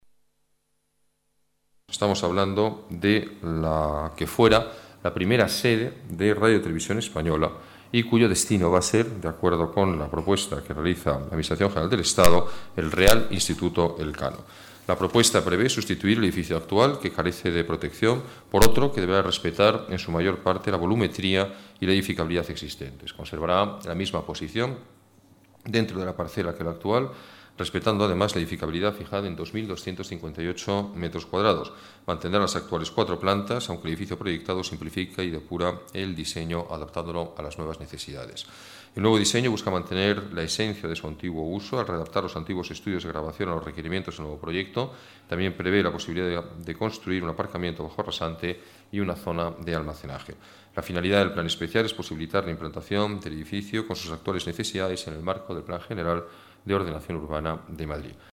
Nueva ventana:Declaraciones del alcalde sobre la futura sede del Real Instituto Elcano en el paseo de la Habana